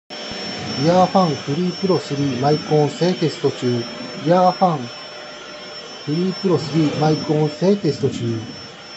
周辺音も拾うけど音声もハッキリ聞こえるレベルに作られています。
✅掃除機を使用した環境
騒音とかはあまり軽減されていないが声もハッキリ聴きとれる。